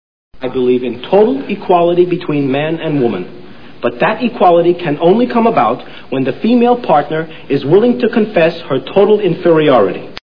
All in the Family TV Show Sound Bites